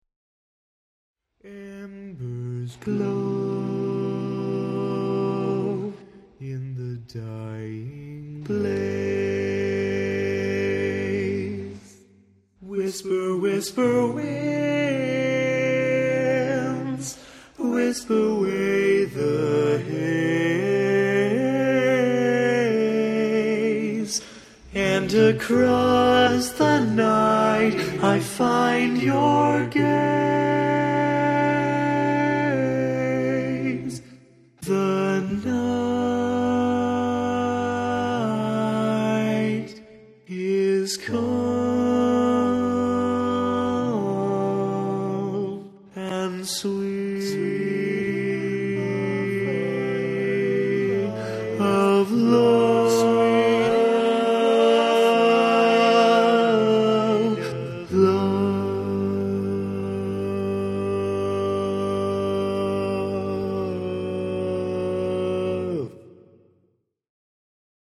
Key written in: E♭ Major
Type: Barbershop